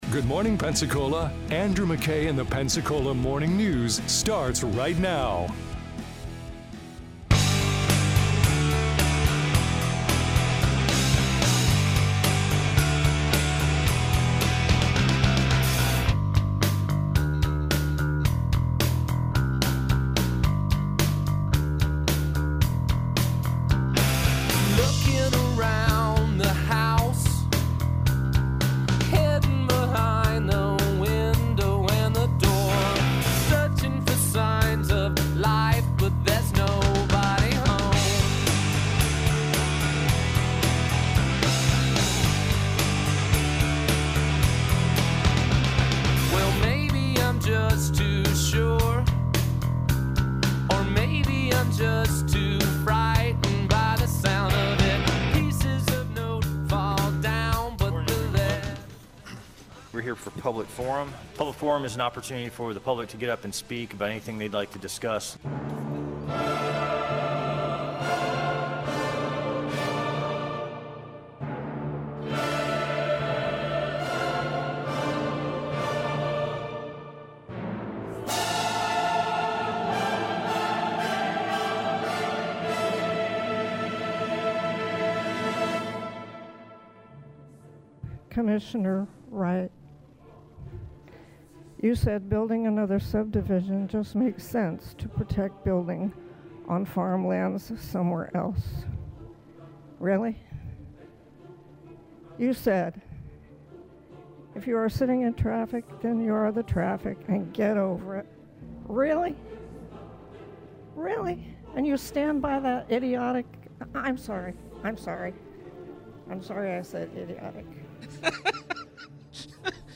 Santa Rosa BOCC Meeting, Replay os US Congressman Jimmy Patronis Interview